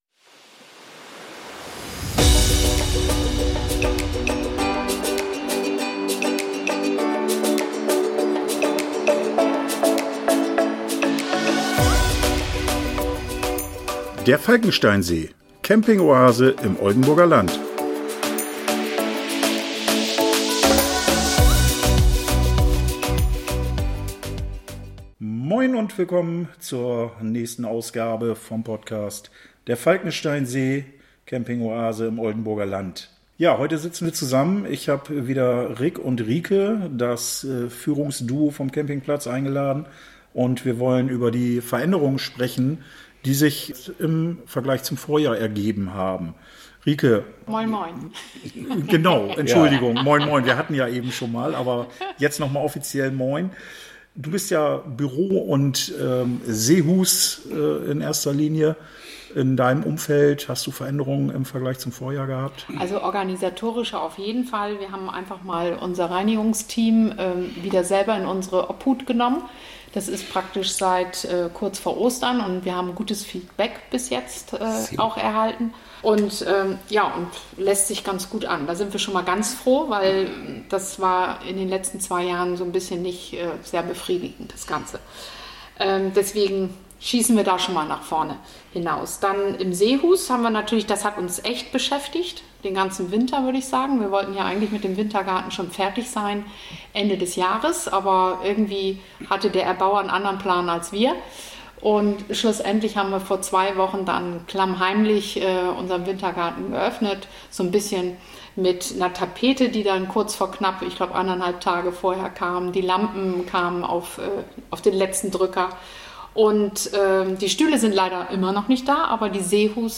Leider mussten wir für diese Aufnahme ins Seestübchen gehen, wo die Akustik nicht ganz so toll ist.